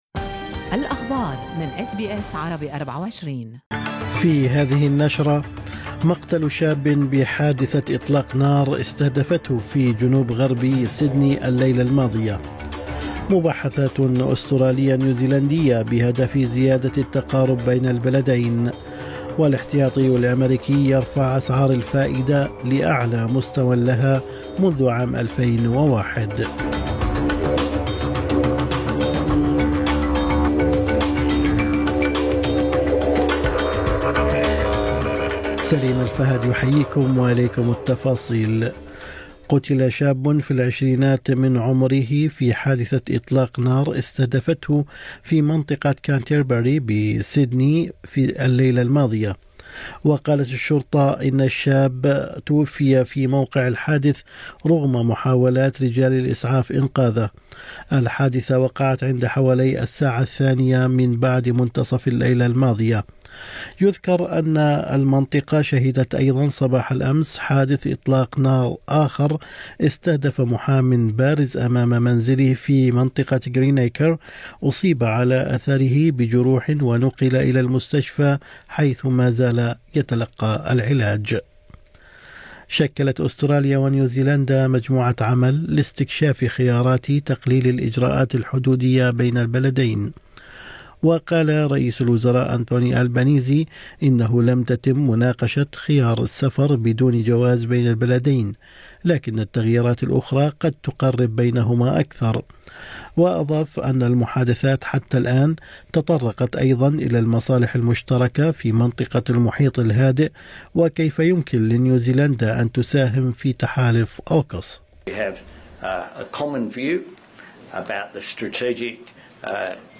نشرة أخبار الصباح 27/7/2023